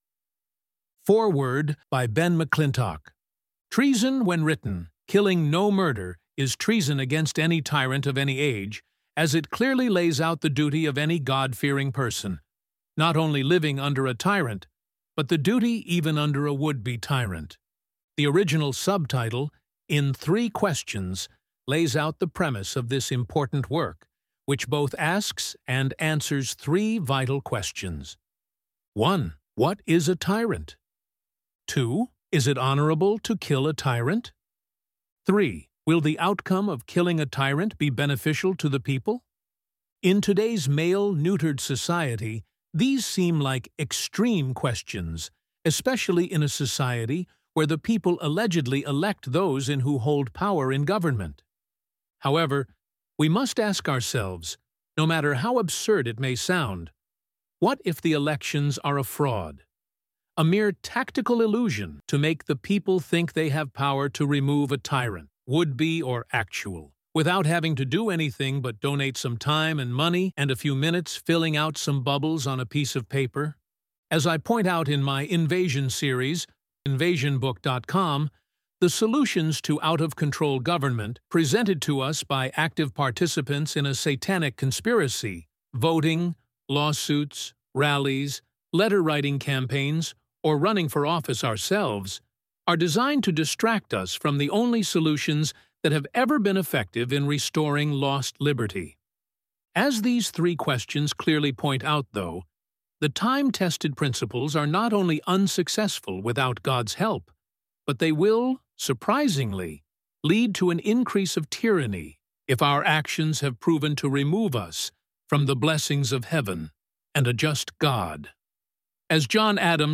Audio book sample: